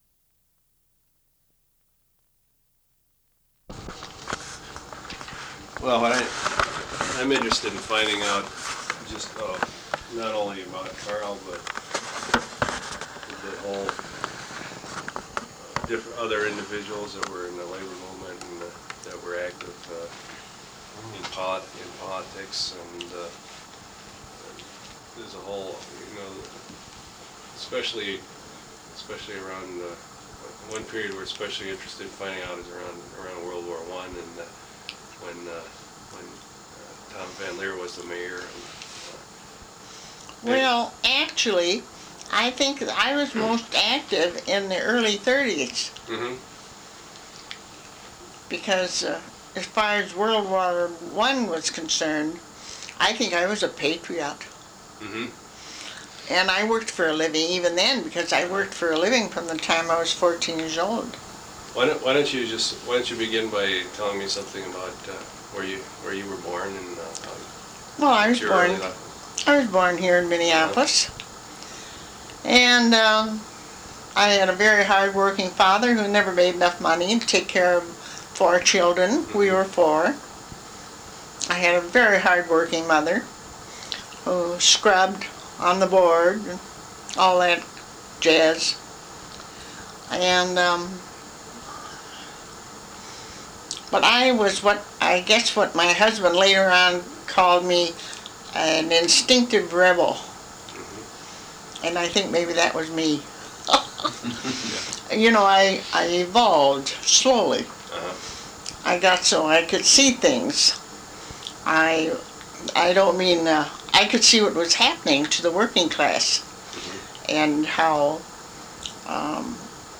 Oral history interview transcripts